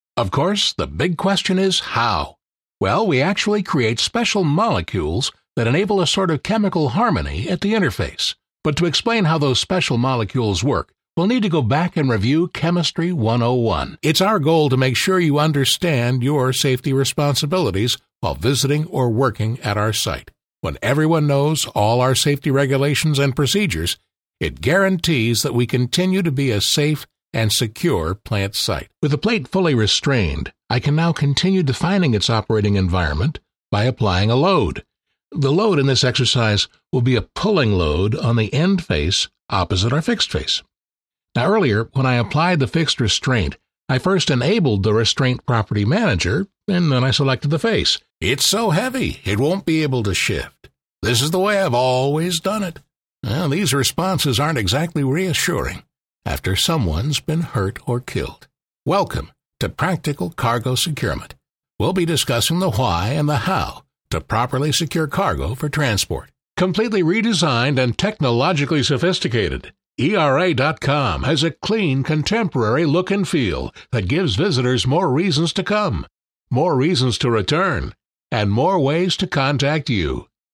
He has a rich, warm voice, a natural style, and can also be very funny.
middle west
Sprechprobe: eLearning (Muttersprache):